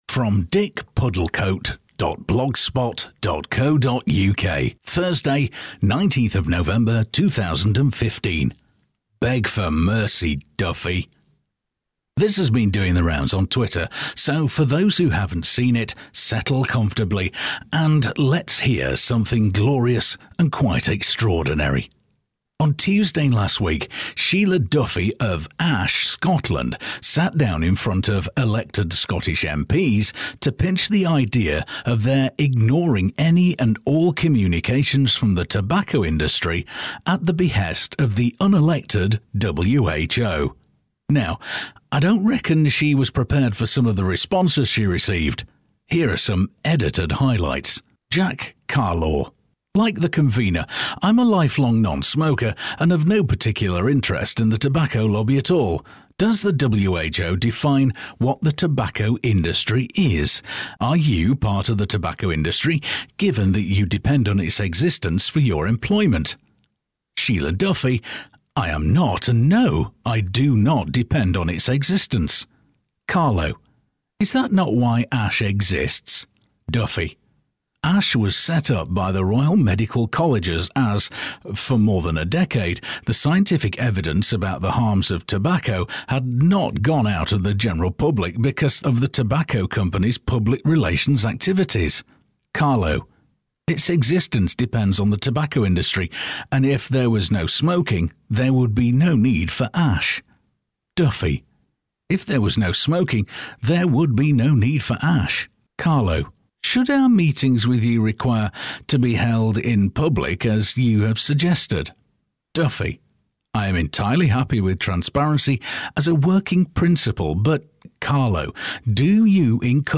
Now, I don’t reckon she was prepared for some of the responses she received. Here are some edited highlights.